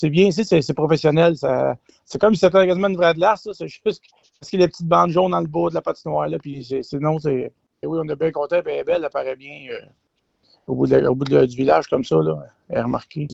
C’est ce qu’il a indiqué en entrevue à propos de la nouvelle installation de la Municipalité qui a été terminée de construire au cours des dernières semaines.